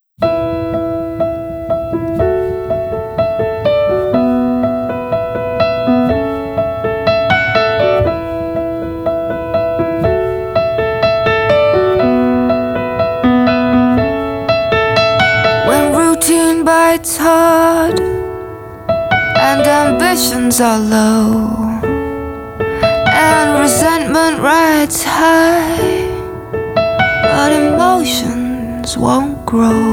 Genre: Singer/Songwriter